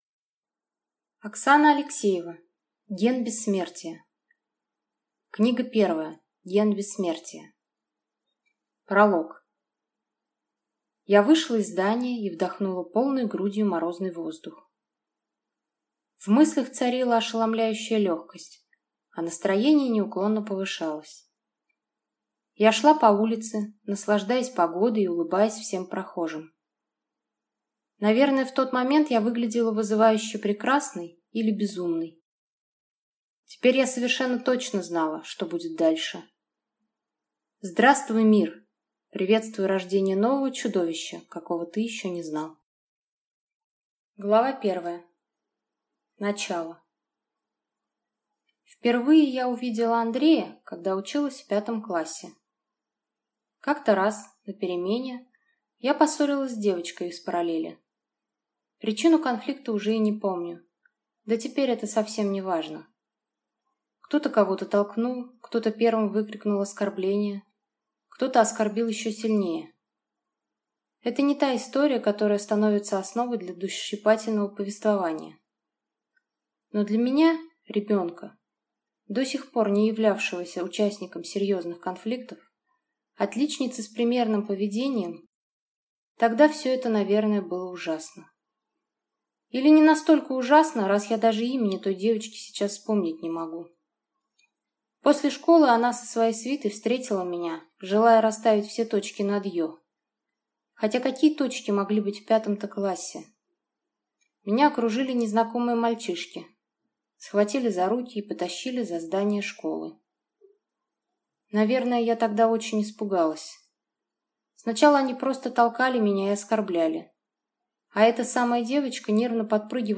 Аудиокнига Ген бессмертия | Библиотека аудиокниг
Прослушать и бесплатно скачать фрагмент аудиокниги